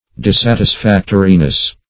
-- Dis*sat`is*fac"to*ri*ness, n.